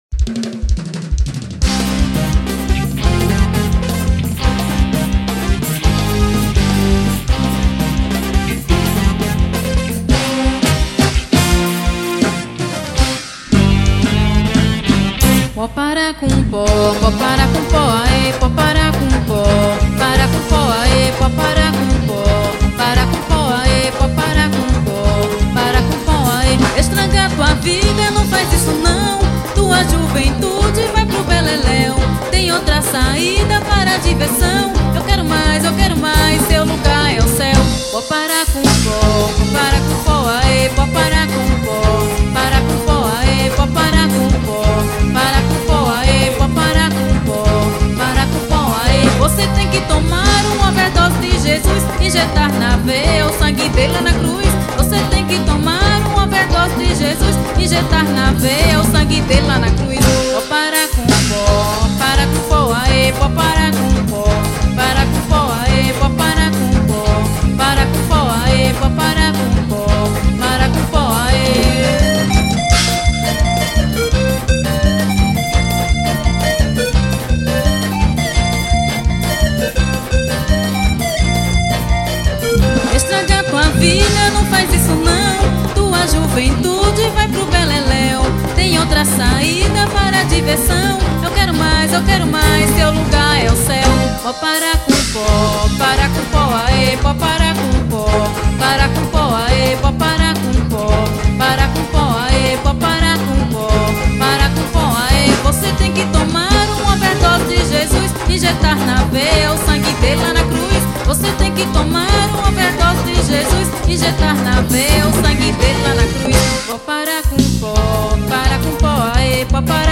gospel.